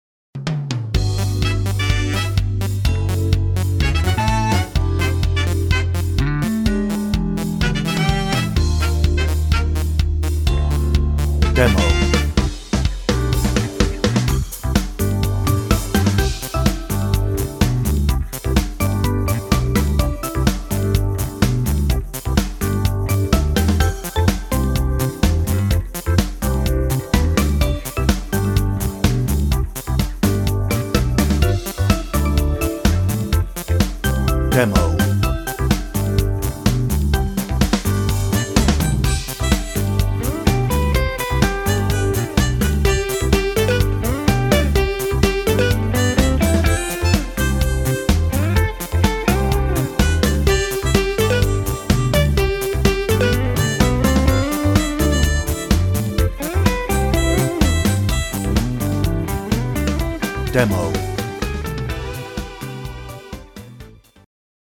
No ref. vocal
Instrumental